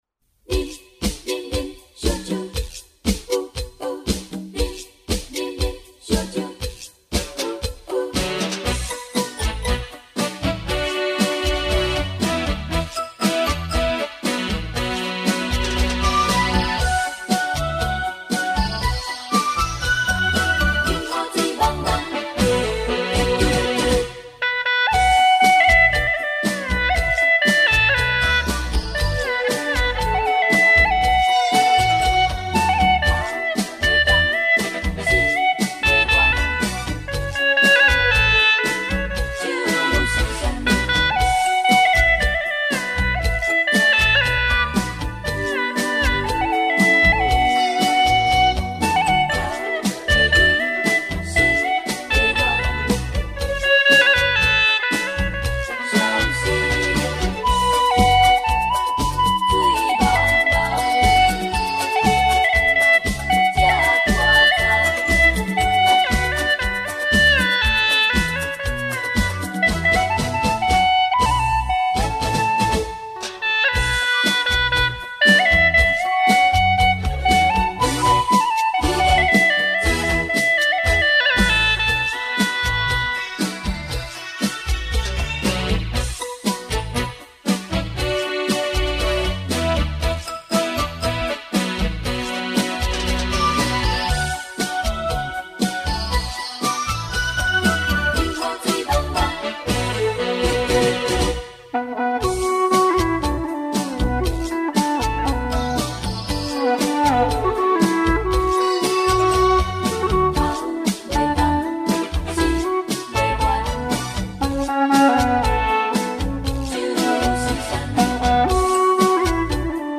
调式 : D 曲类 : 流行 此曲暂无教学 点击下载 又一首网络神曲，闽南歌曲，节奏感强，适合跳广场舞